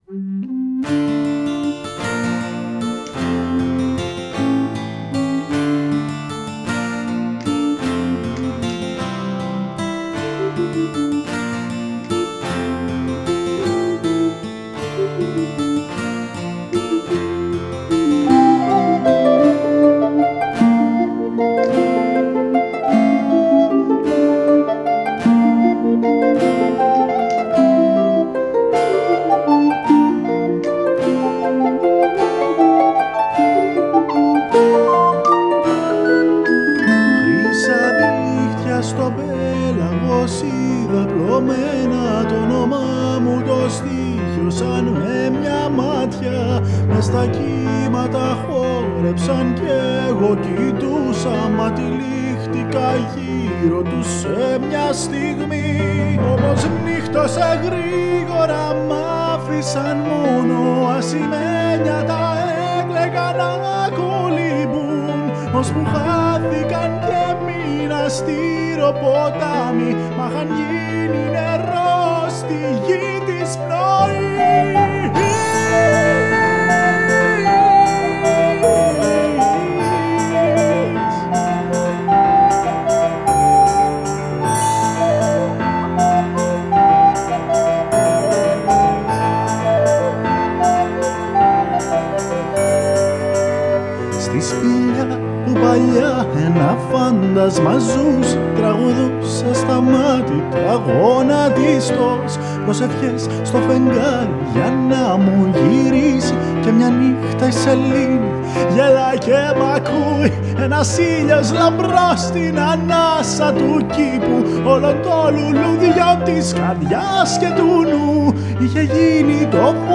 which I quickly recorded at my home-studio
Excuse the bad quality and the poor performance.
All instruments by me
Some whispers and cries, also by me.